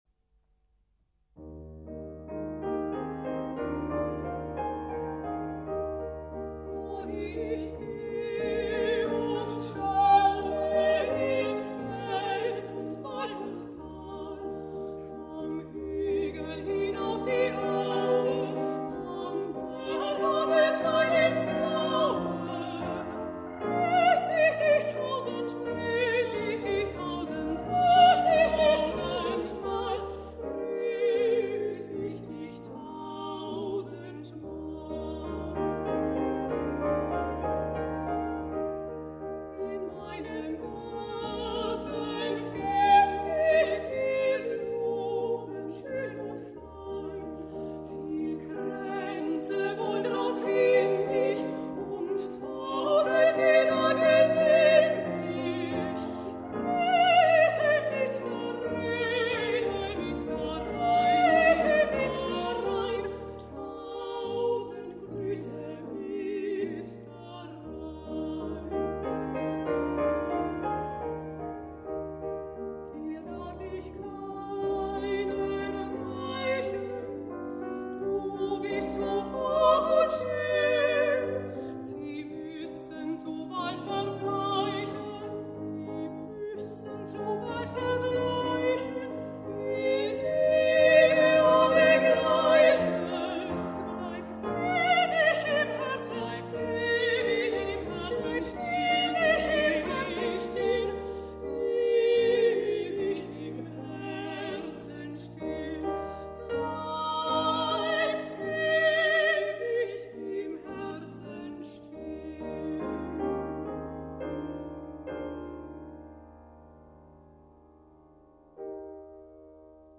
Sopran
Alt
Klavier